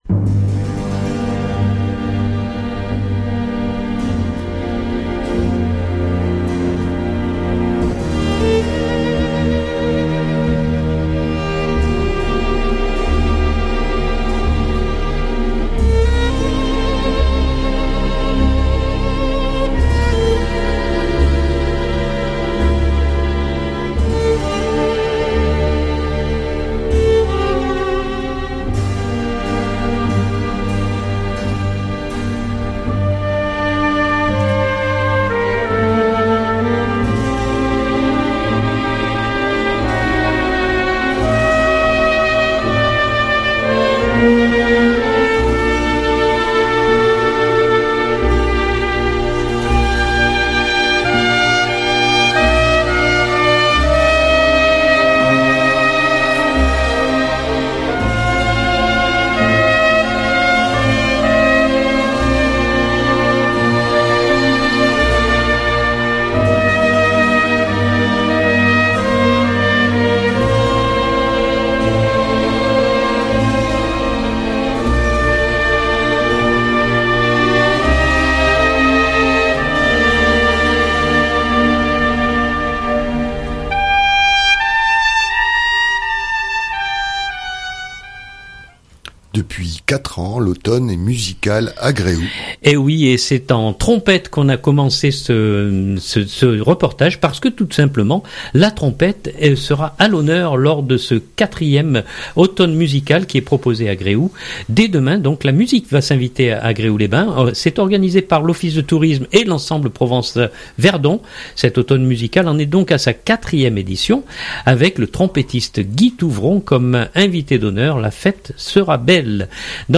Dans nos studios pour évoquer ces 4 jours de musique